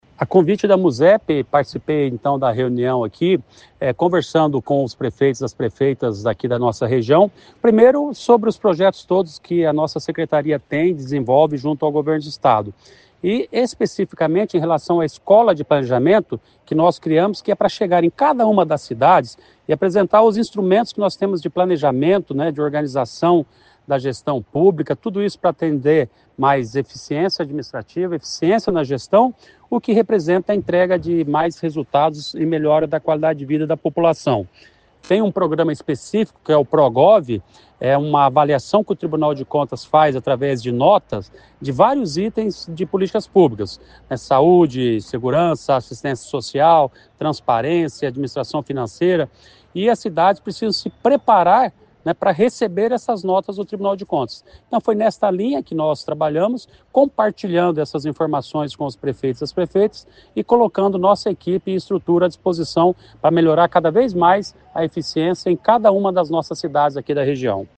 Ouça o que diz o secretário Ulisses Maia.